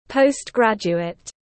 Nghiên cứu sinh tiếng anh gọi là postgraduate, phiên âm tiếng anh đọc là /ˌpəʊstˈɡrædʒ.u.ət/.
Postgraduate /ˌpəʊstˈɡrædʒ.u.ət/